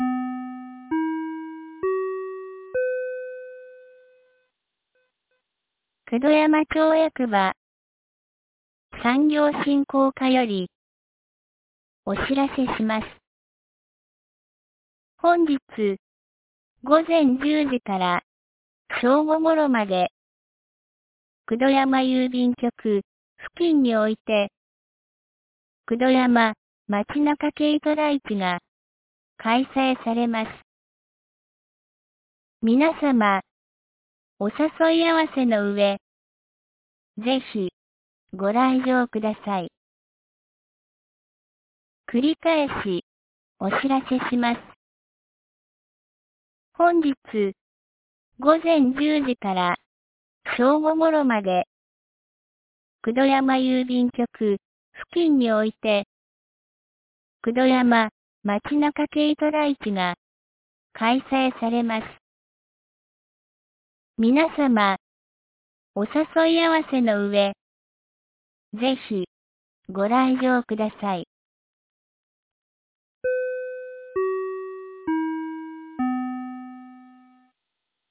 2025年09月21日 09時01分に、九度山町より全地区へ放送がありました。